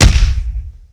HeavyHit2.wav